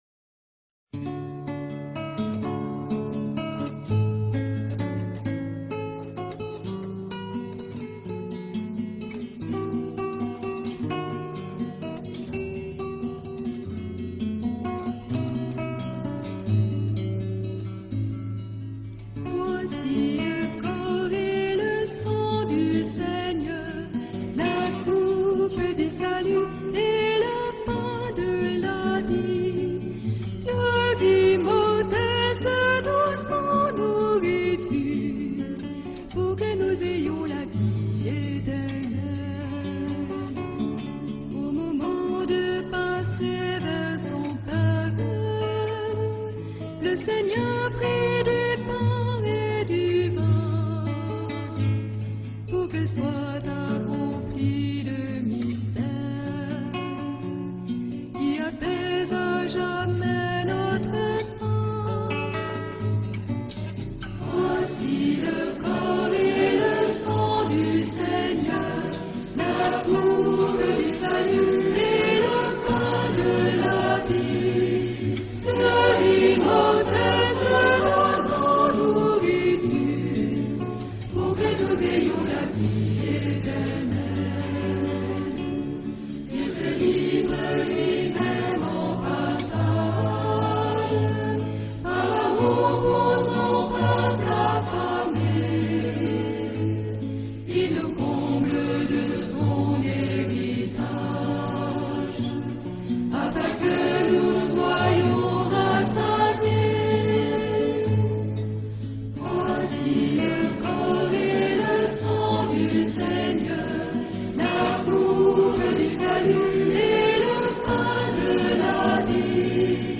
Voici-le-corps-et-le-sang-du-seigneur-Chant-catholique-.mp3